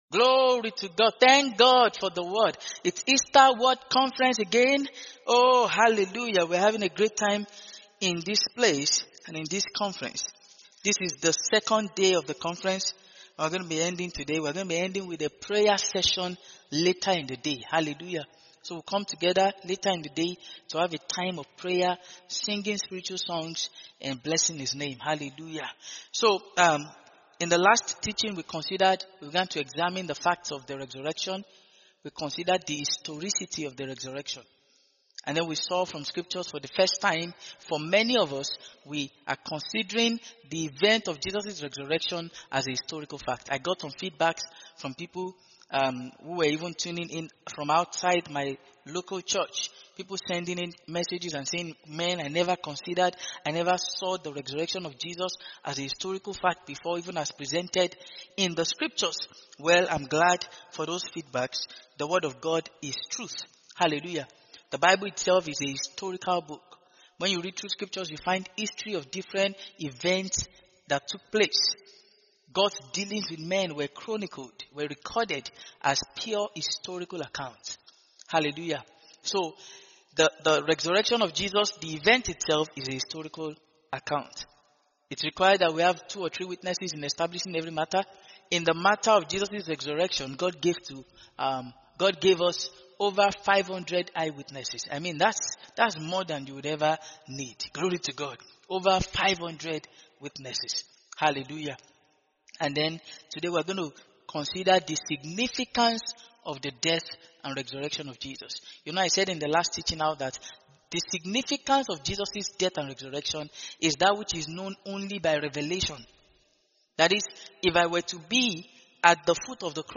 The Reality of His Resurrection - Part 2 (Its Revelation) - TSK Church, Lagos